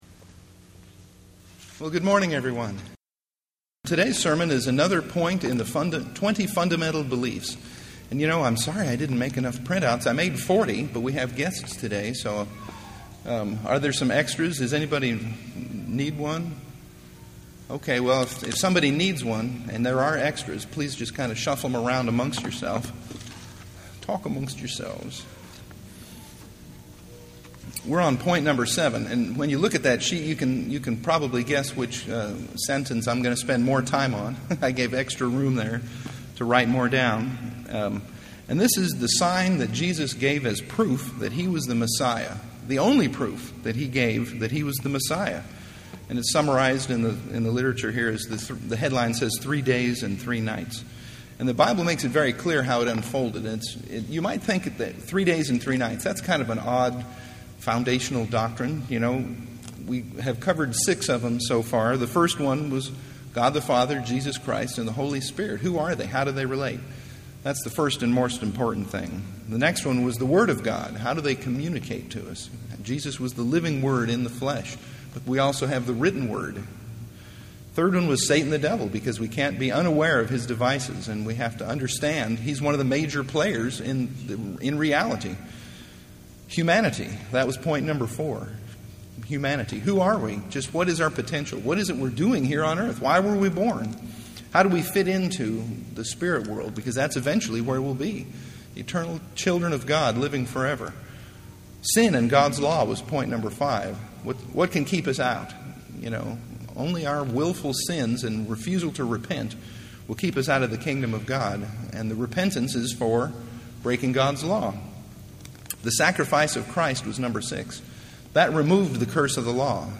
Print Exploring Christ's only promised sign of His Messiahship -- three days and three nights in the heart of the earth UCG Sermon Studying the bible?